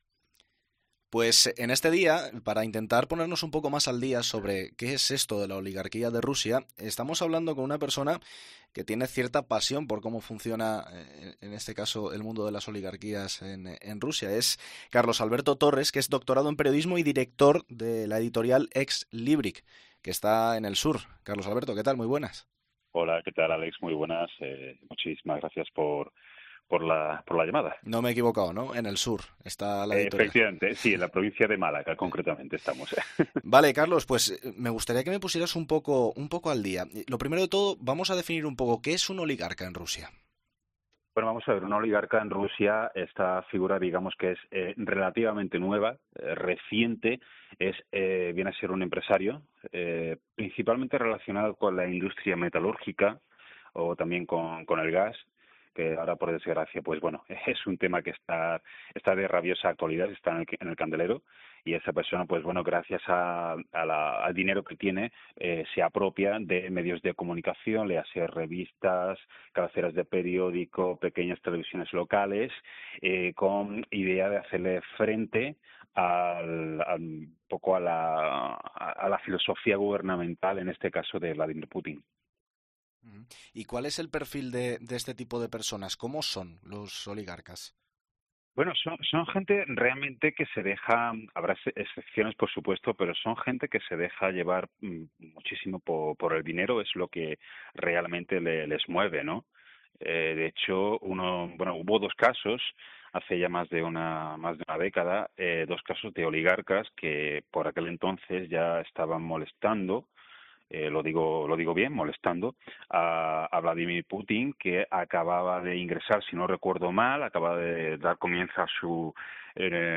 ha pasado por los micrófonos de COPE para intentar explicar en qué consiste la figura del oligarca en el país soviético, y por qué es tan complicado definirlo.